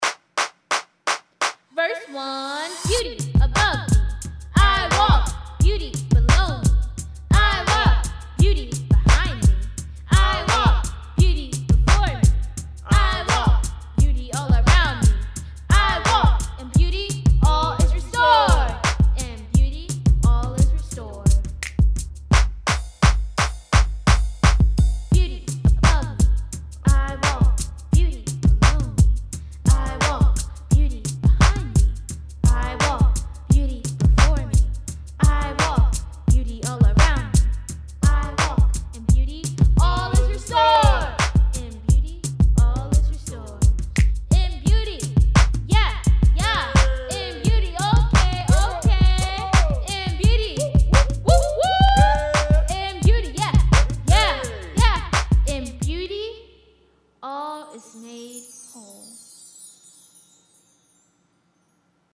Audio Track with Vocals